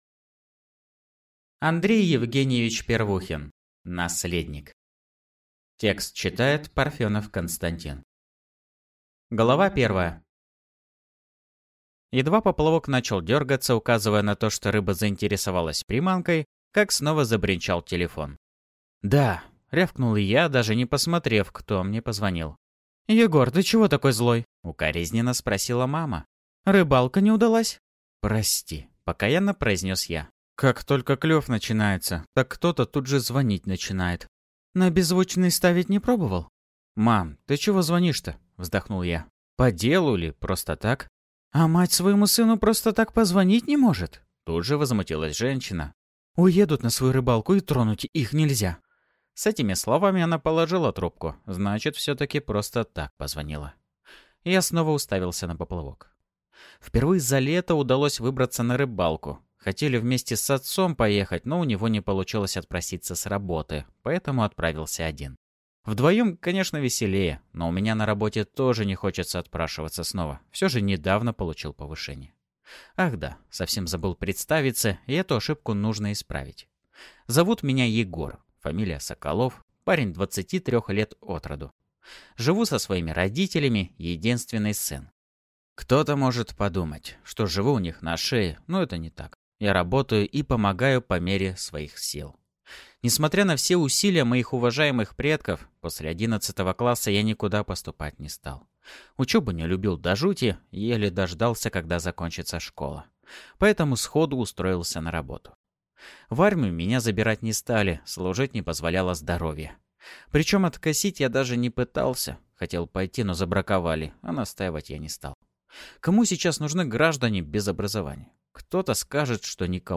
Зверский детектив. Тёмное прошлое. Пальмовый дневник каракала полиции (слушать аудиокнигу бесплатно